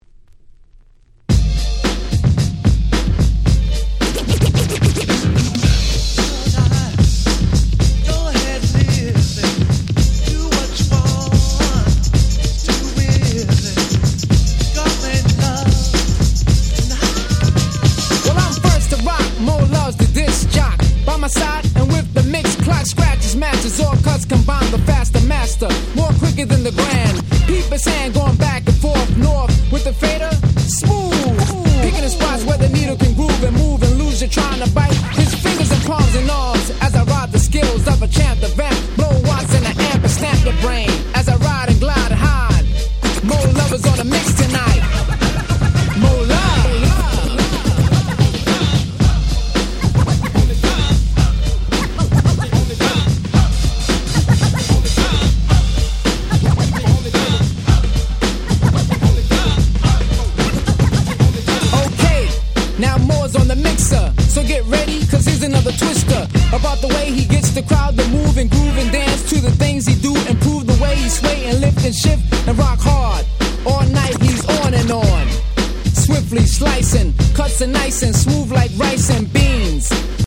89' Super Hip Hop Classics !!
80's Middle School ミドルスクール